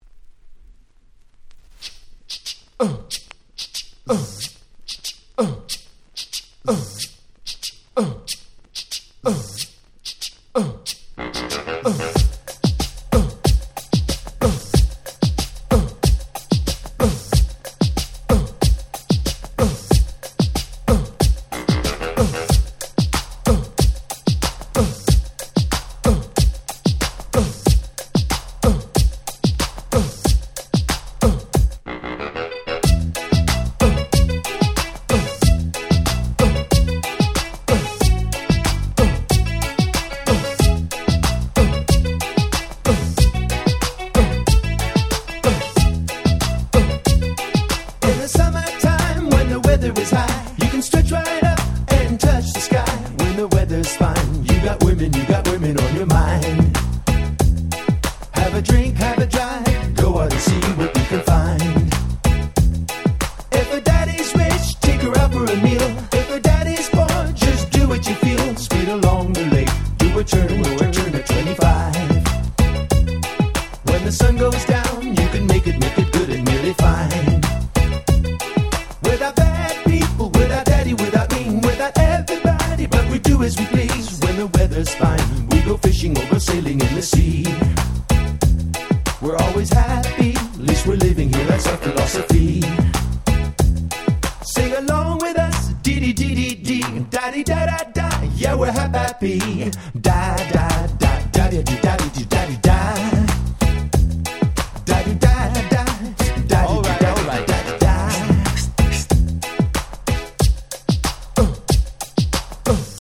93' Nice Ground Beat Cover !!
ディスコマジック 90's エースビート Grand キャッチー系 R&B